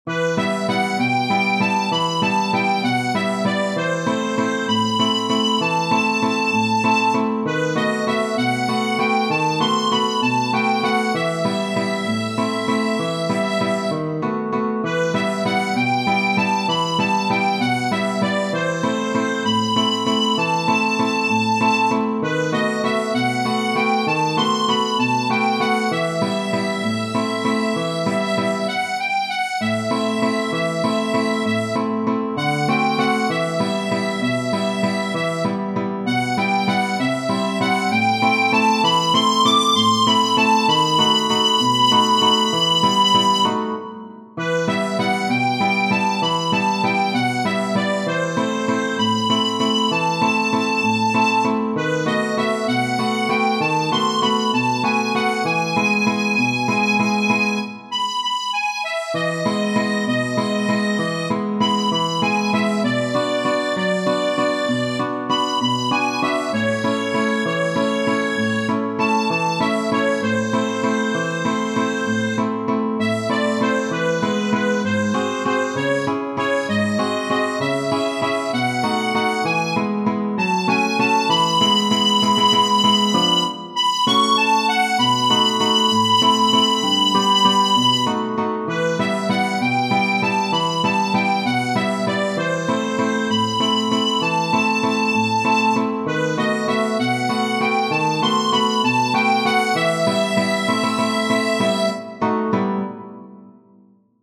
Genere: Ballabili